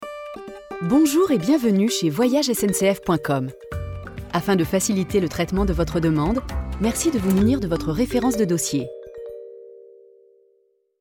Serveur vocal : Voyages SNCF